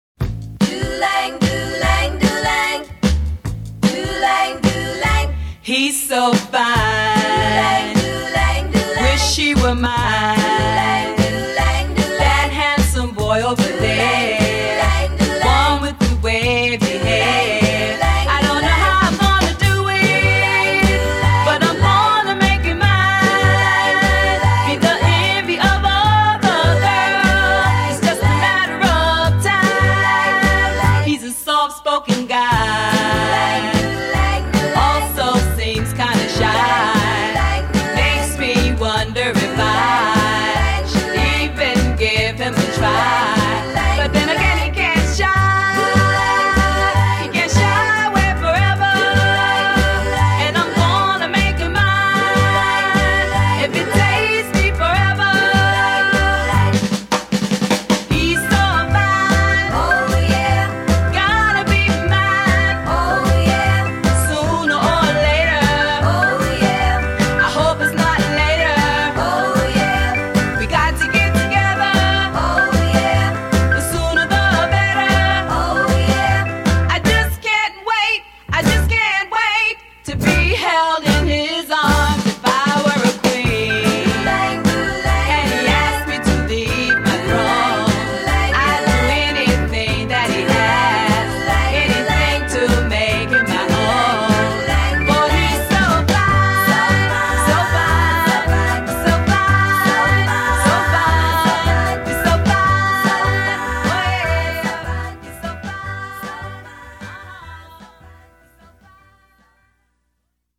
американского женского квартета